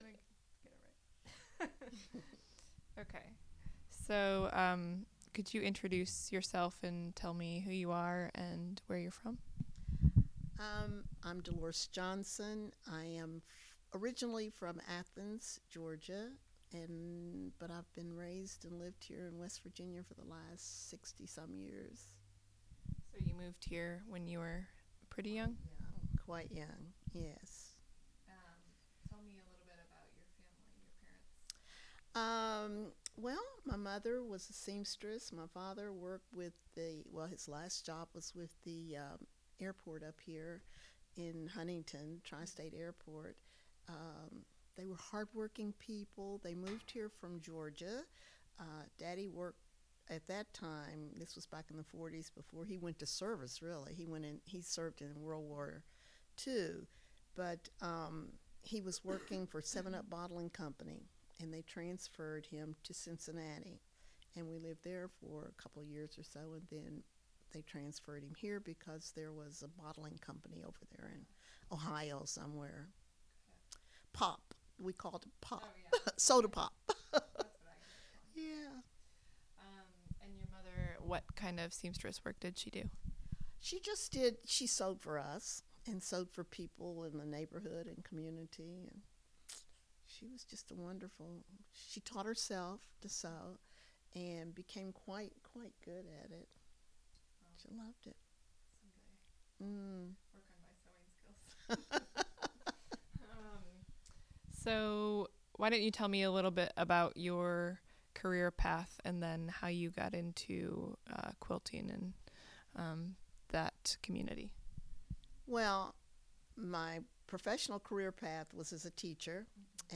Oral history
Subject: Quilting , African American quilts , African Americans--Appalachian Region , Quiltmakers , Art quilts , and Portraits on quilts Rights: In Copyright - Educational Use Permitted Location: Huntington (W. Va.)